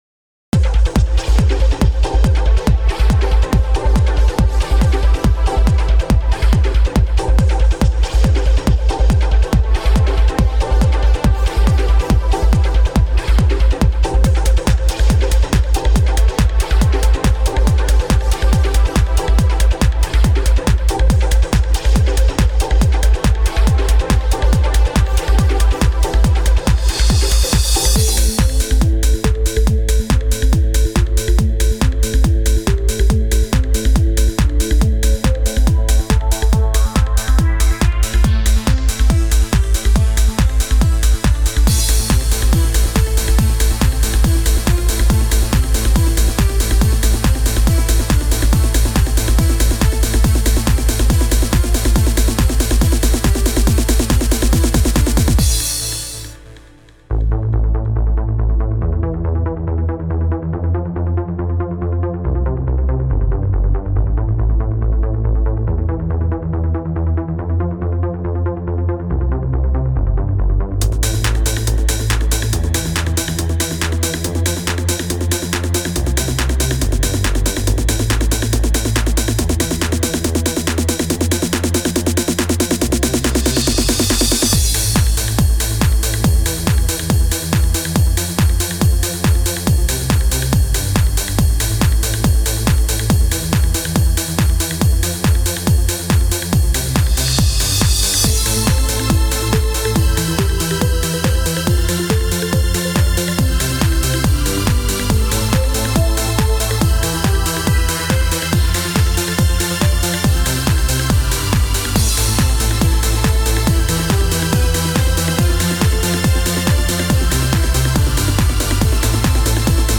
Trance
自分なりのトランスを作ってみたの（テーマは〇VEX風に作る）。